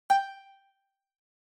Click Button Menu